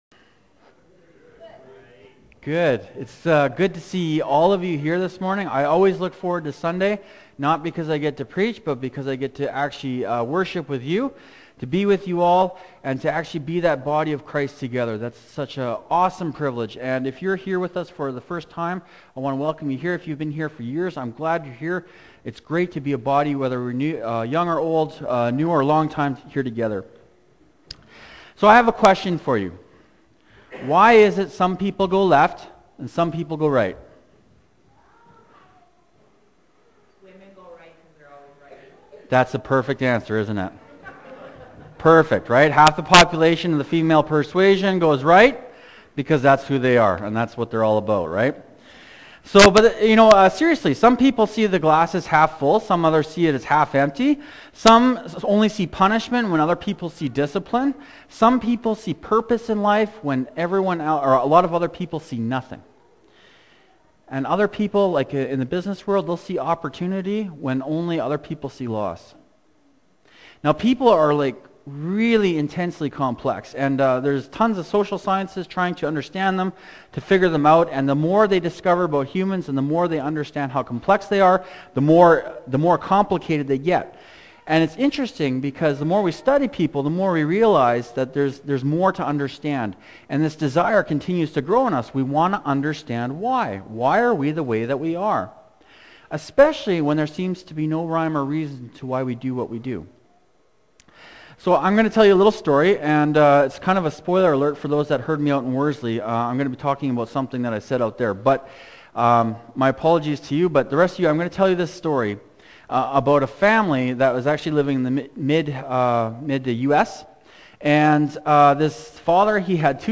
Series: 2019 Sermons, Jeremiah